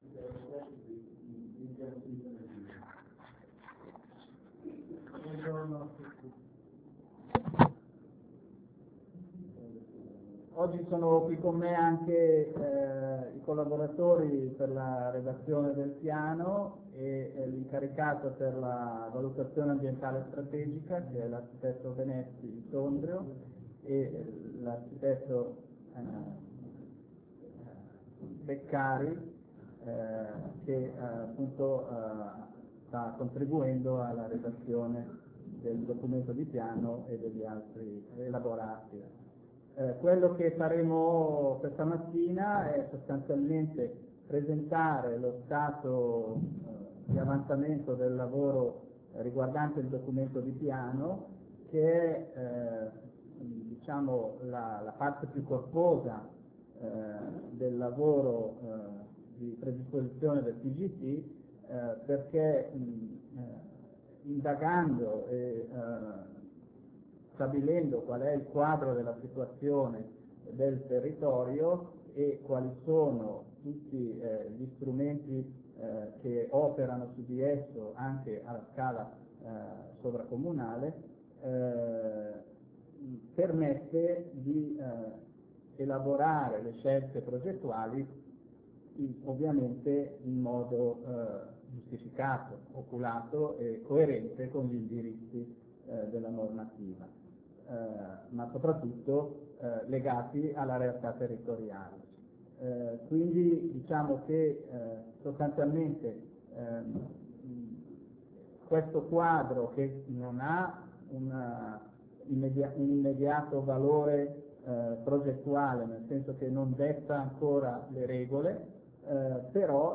PGT Ballabio - 2a Assemblea Pubblica 01 Marzo 2008
2a Assemblea Pubblica PGT per la Ballabio del futuro